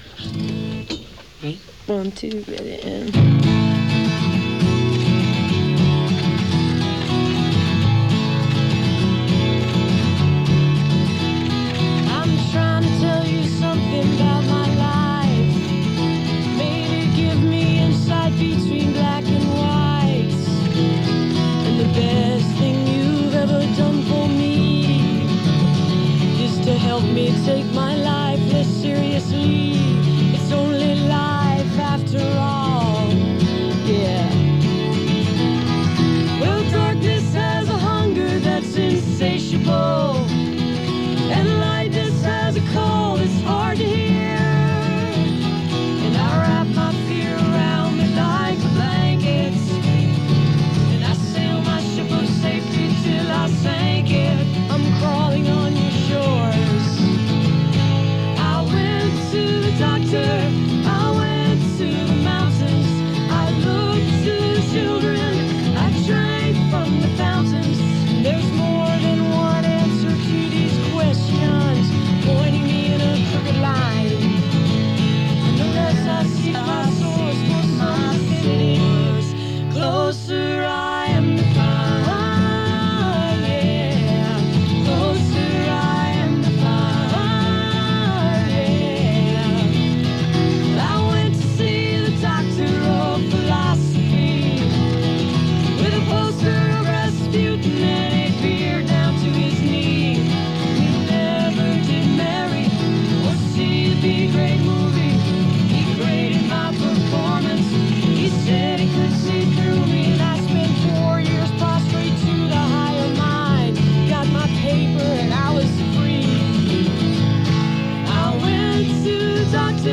(acoustic duo show)
live in the studio